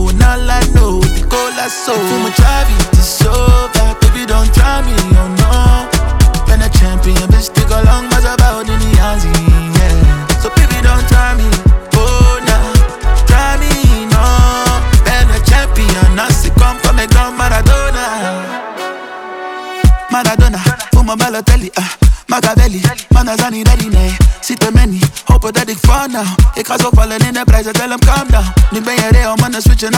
Жанр: Поп / Африканская музыка